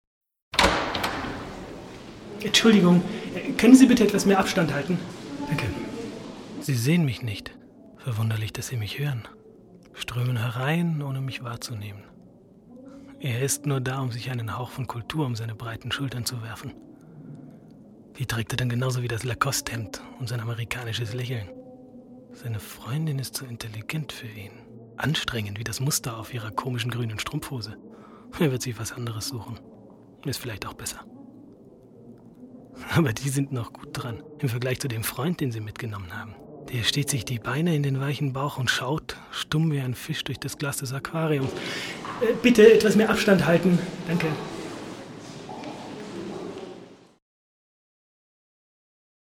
deutscher Sprecher und Schauspieler
schweizerdeutsch
Sprechprobe: Industrie (Muttersprache):